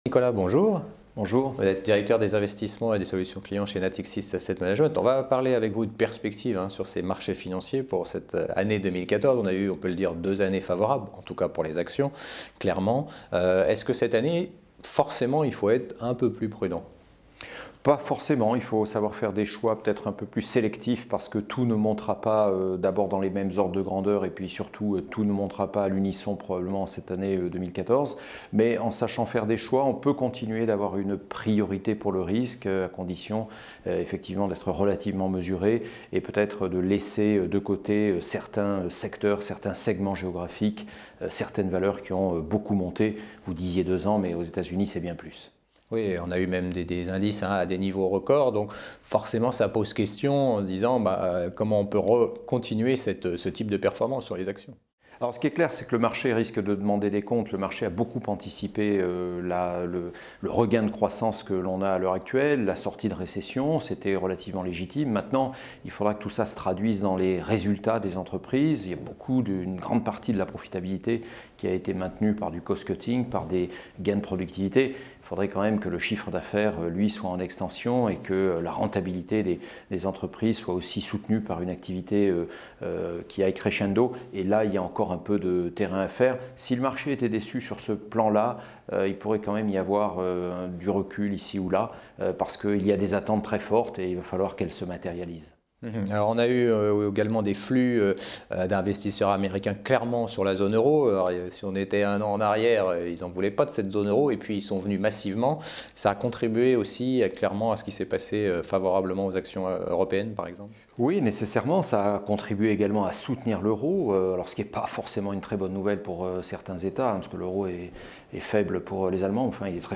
Bourse : Interview